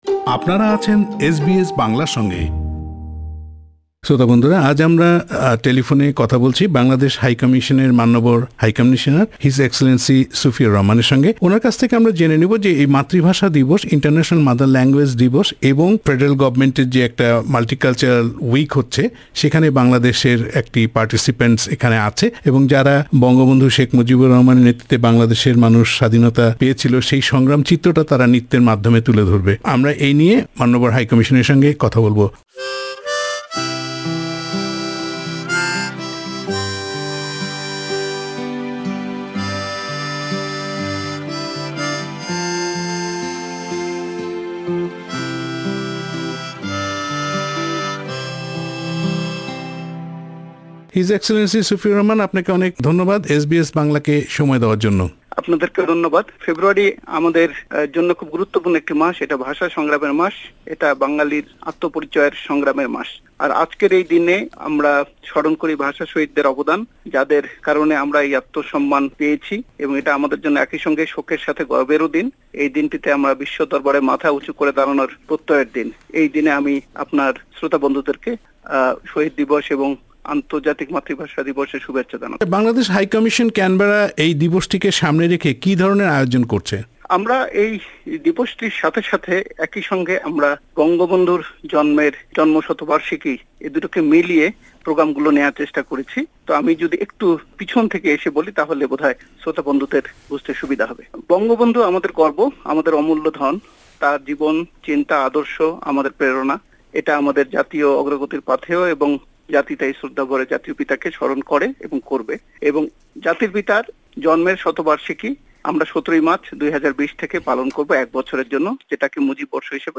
এসব বিষয় নিয়ে এসবিএস বাংলার সঙ্গে কথা বলেছেন বাংলাদেশের মান্যবর হাই কমিশনার সুফিউর রহমান।